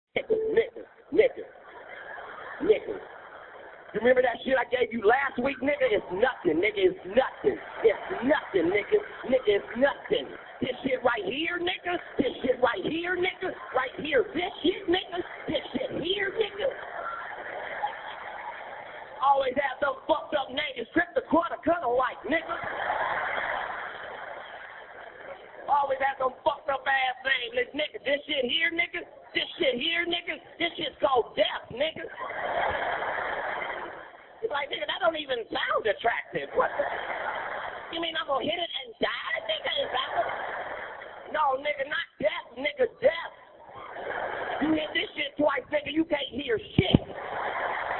• Voice Tones Ringtones